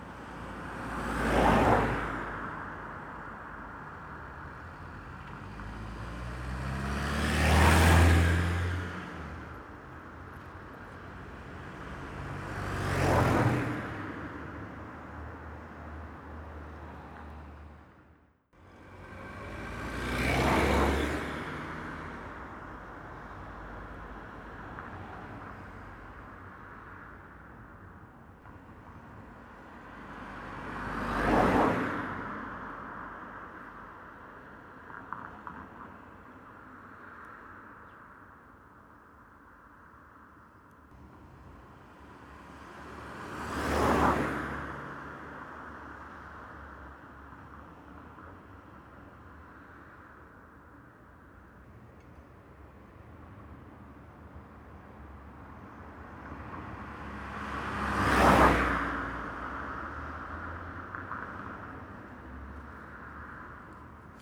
car-passing-by.wav